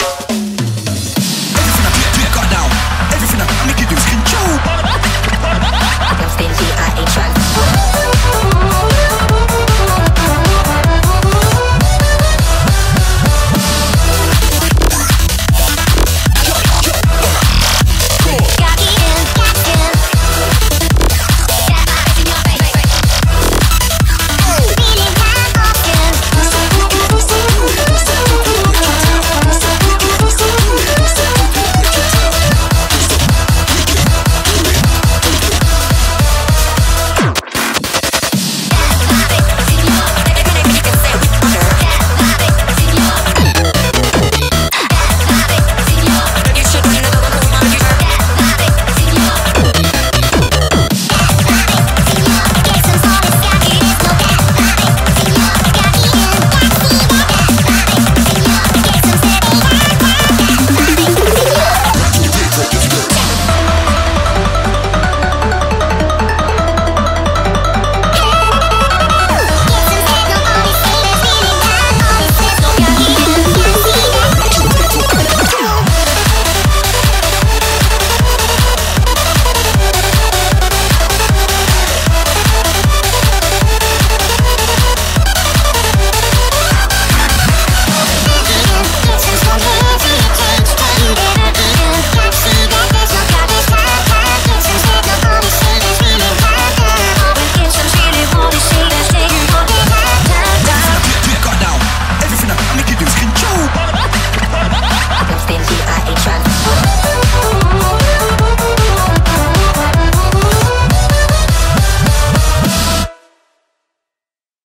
BPM155